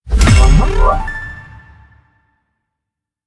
match-confirm.ogg